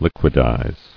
[liq·ui·dize]